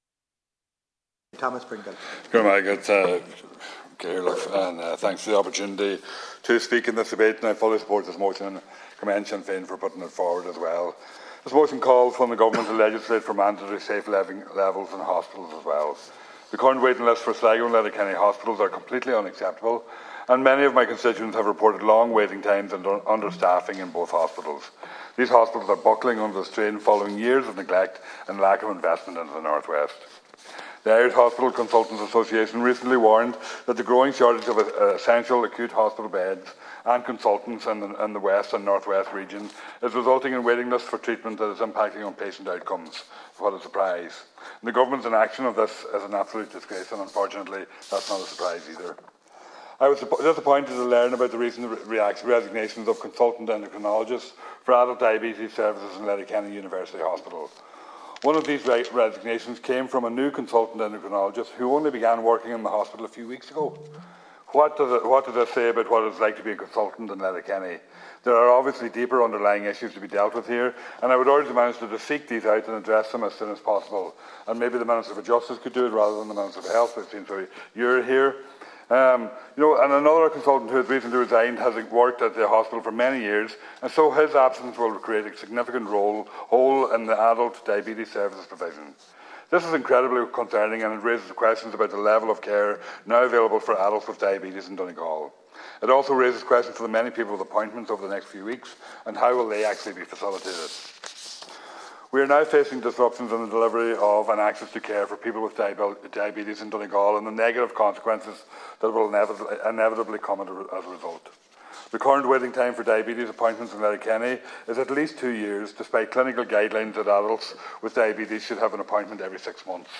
Deputy Thomas Pringle said in the Dáil this week that many of his constituents have raised the long wait-times in the Emergency Department.